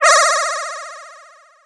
SpecialWarp.wav